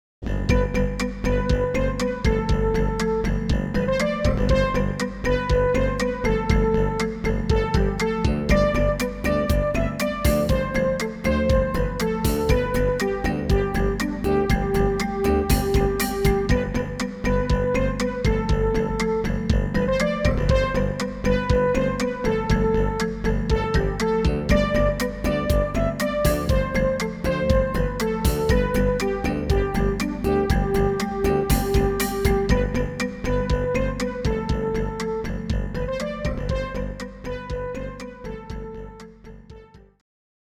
as recorded directly from the Roland MT-32 Sound Module